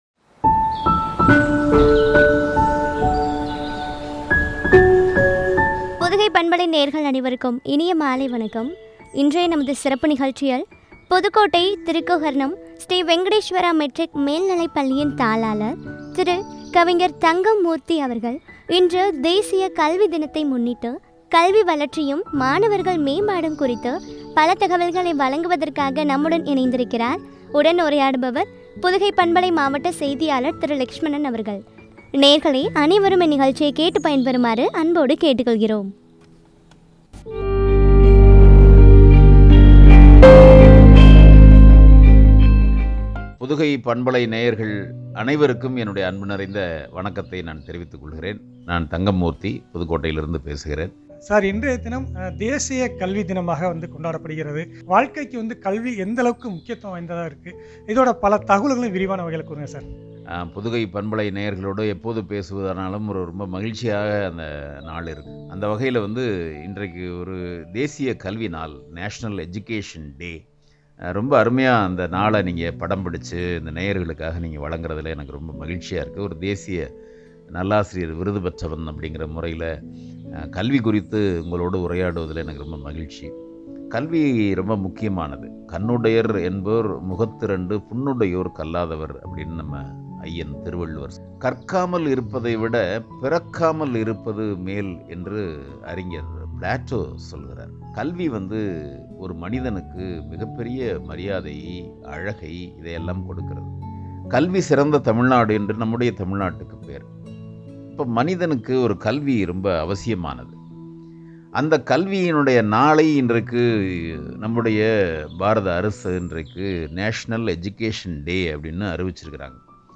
மாணவர்கள் மேம்பாடும் பற்றிய உரை.